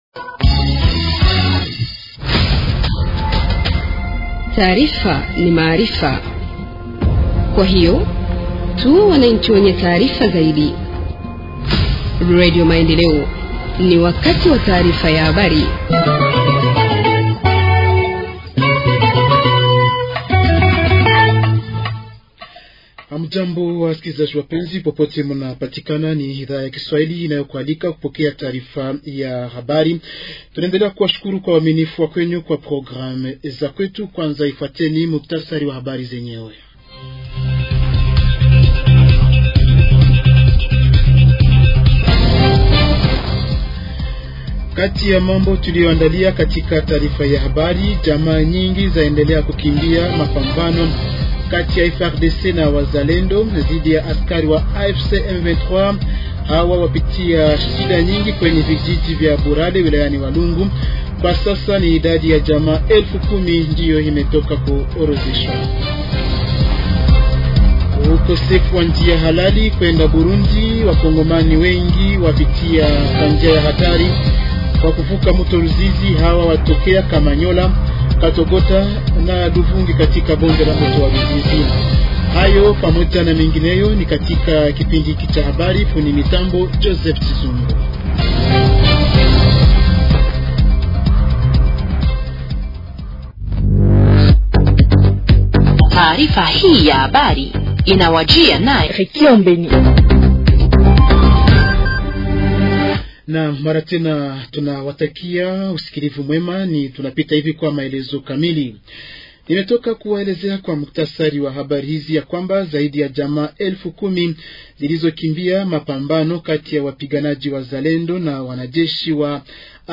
Journal en Swahili du 22 mars 2025 – Radio Maendeleo